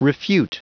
Prononciation du mot refute en anglais (fichier audio)
Prononciation du mot : refute